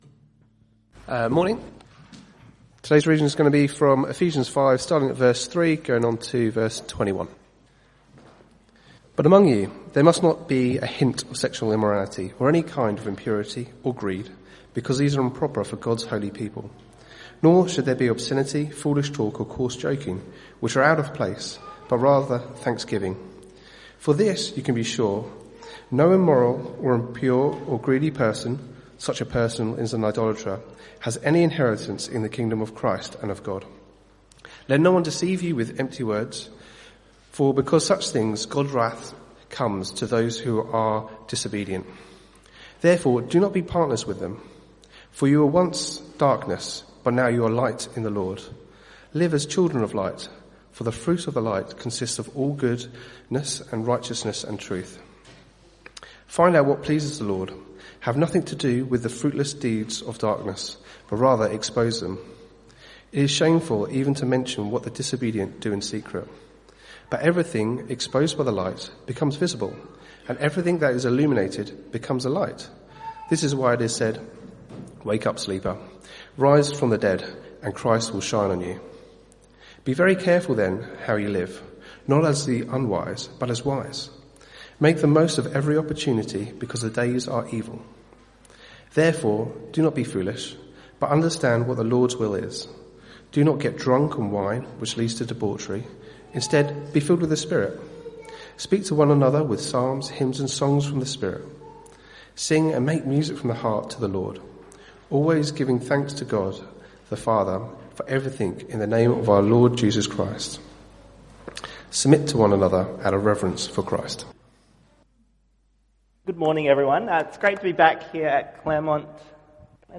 CBC Service: 20 July 2025 Series
Type: Sermons